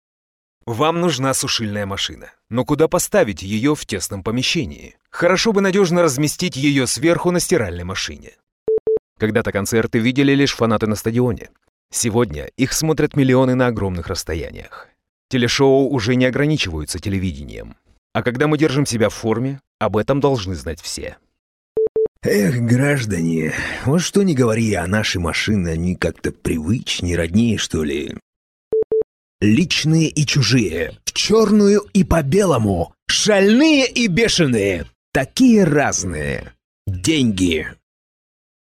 商业广告